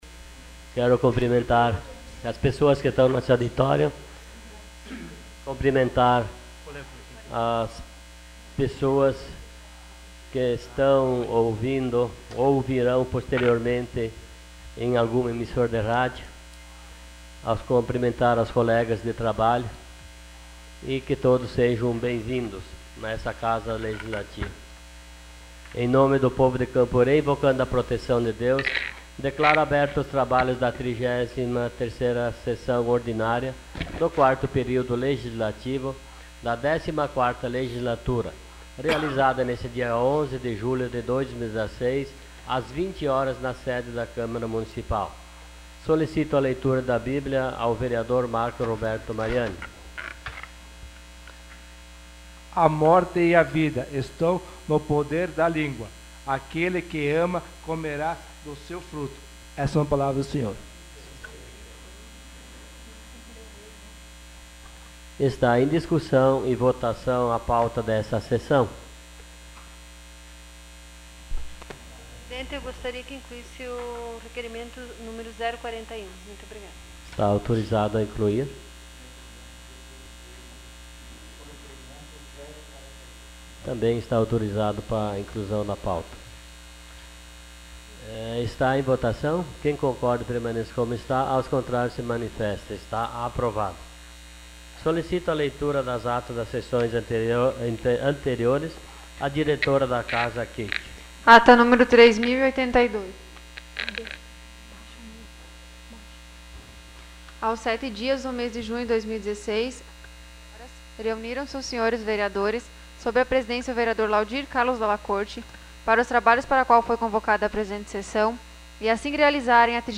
Sessão Ordinária dia 11 de julho de 2016.